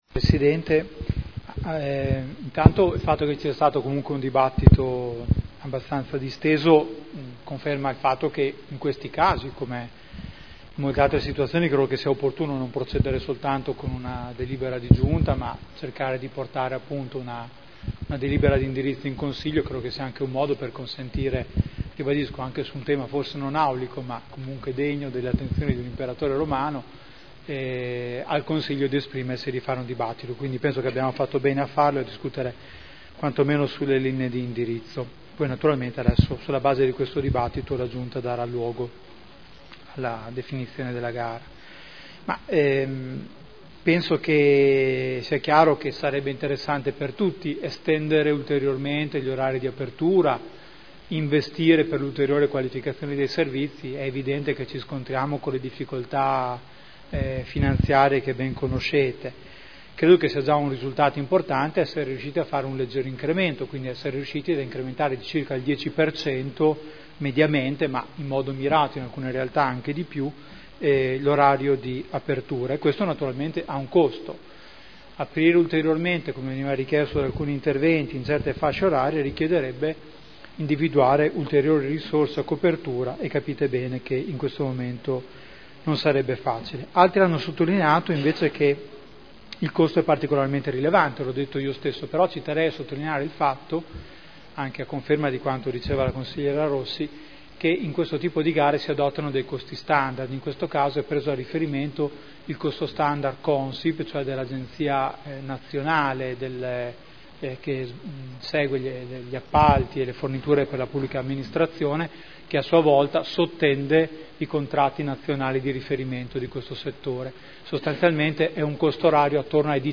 Seduta del 12/11/2012 Conclusioni.